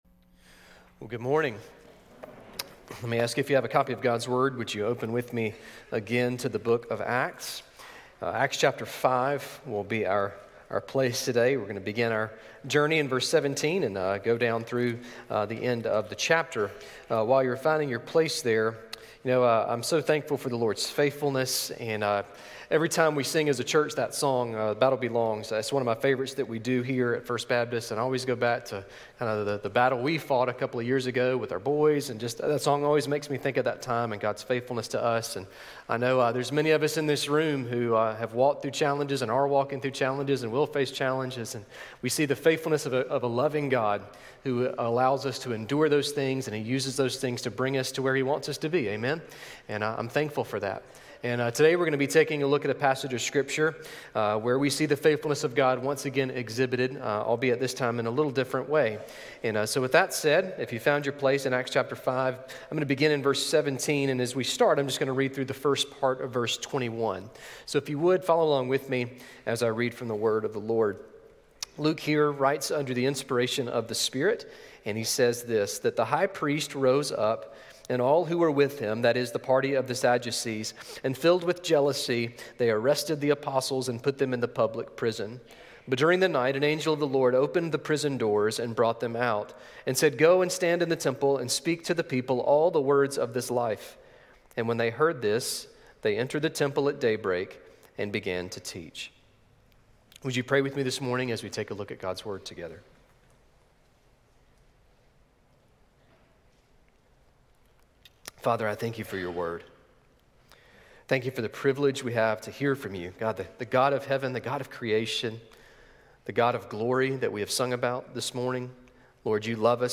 A message from the series "Go."